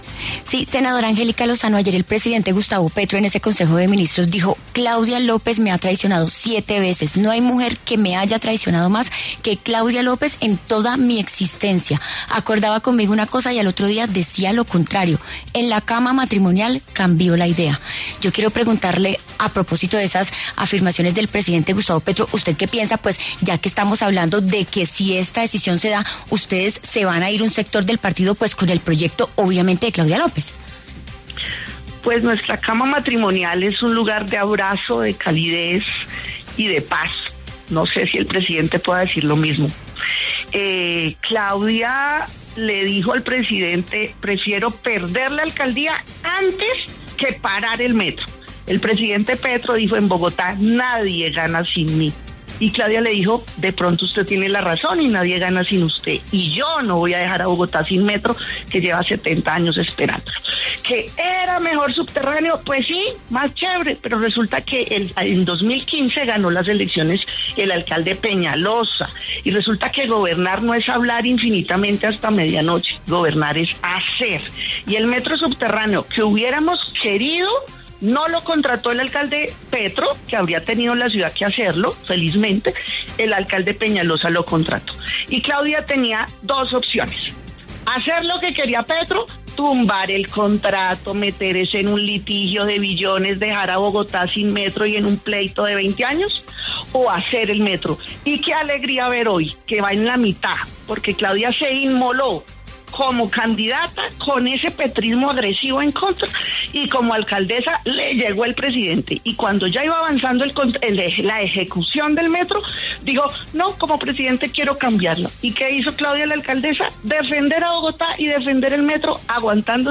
La senadora Angélica Lozano respondió en La W a los señalamientos del presidente Petro sobre una traición por parte de la exalcaldesa Claudia López.